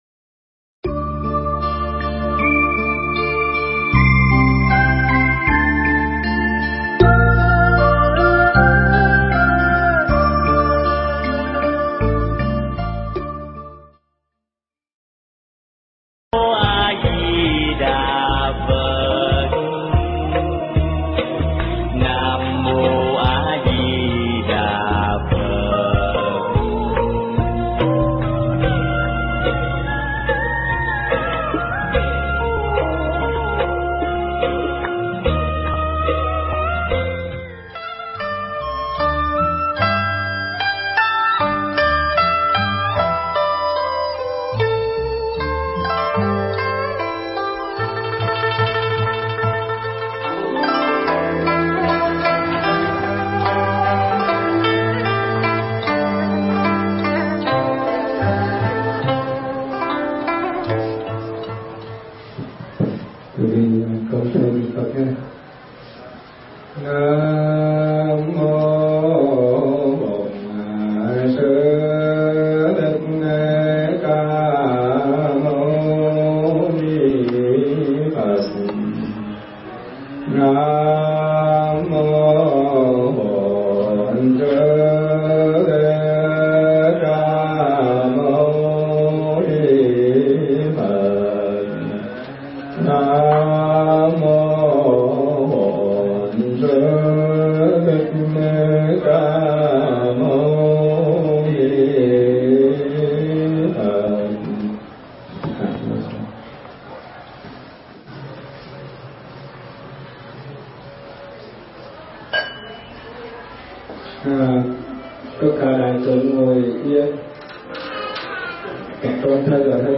Mp3 Ai Cướp Mất Hạnh Phúc Của Chúng Ta – Thuyết pháp